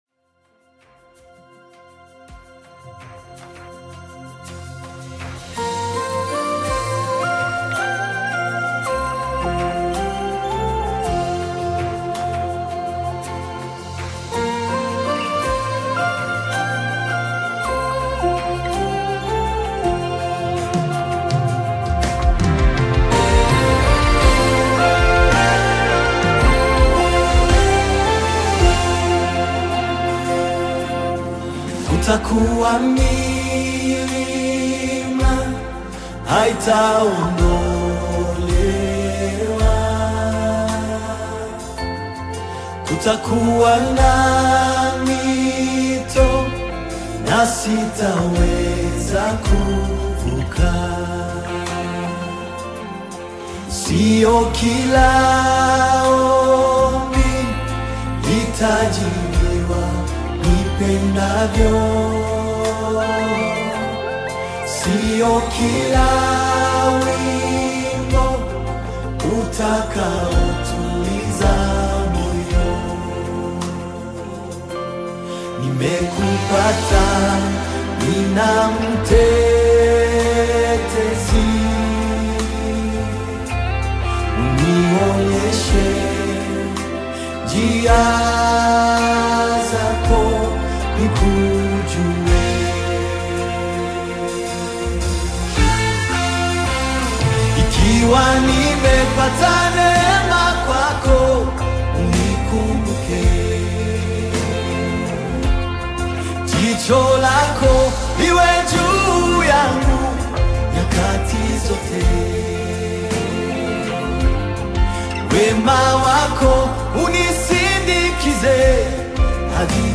Genre: Gospel/Christian.